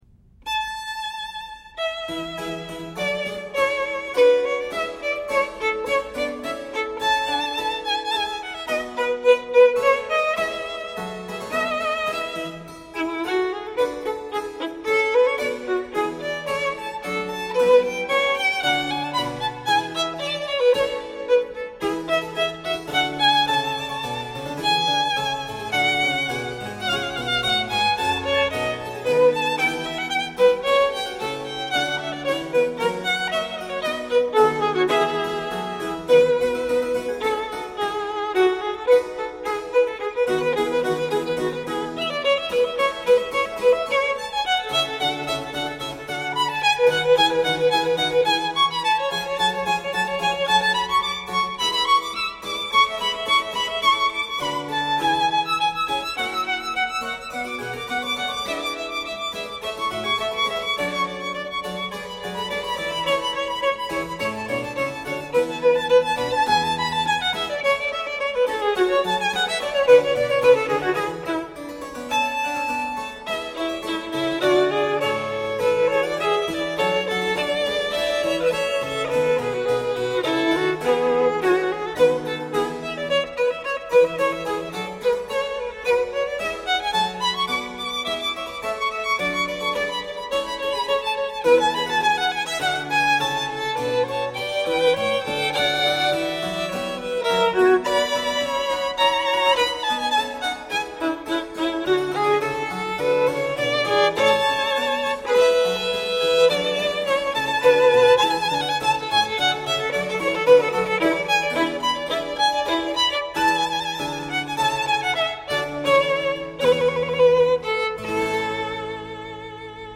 这里面担任主角的是小提琴，通常由羽管键琴或弦乐低音给与伴奏。